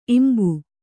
♪ imbu